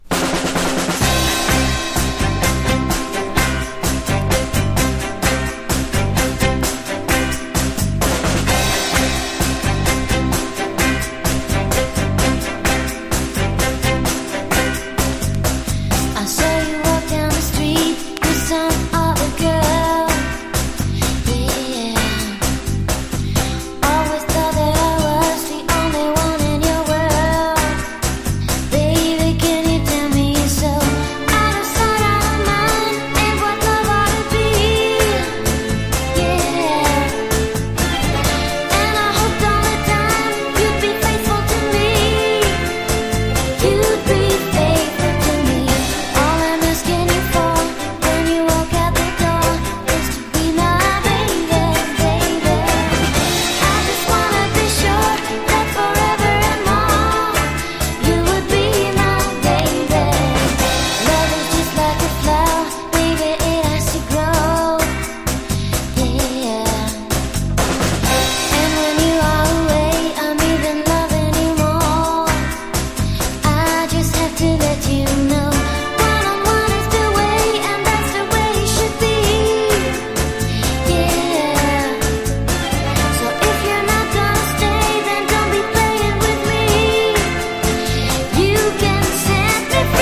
可愛らしい歌声がマッチしたポップな'90sサウンド！